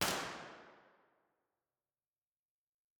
impulseresponseheslingtonchurch-005.wav